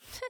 YX嘲弄2.wav 0:00.00 0:00.30 YX嘲弄2.wav WAV · 26 KB · 單聲道 (1ch) 下载文件 本站所有音效均采用 CC0 授权 ，可免费用于商业与个人项目，无需署名。
人声采集素材